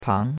pang2.mp3